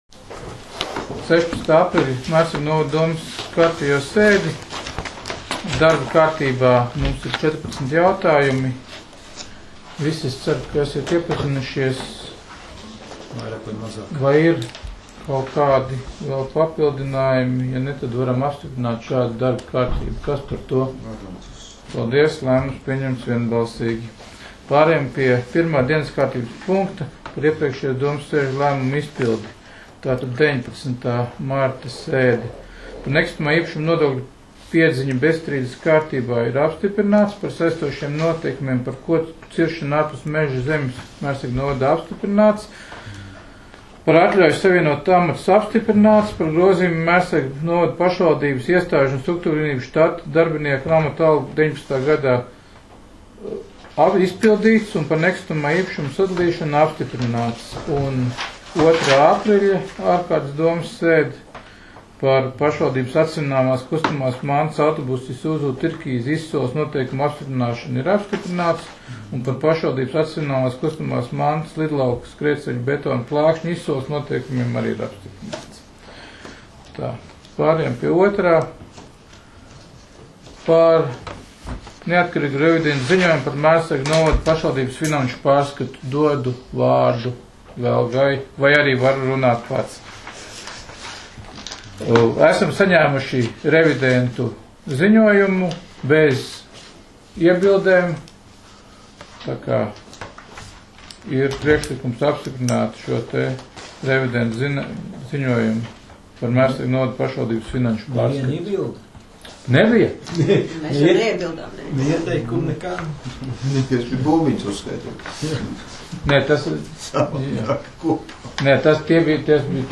Mērsraga novada domes sēde 16.04.2019.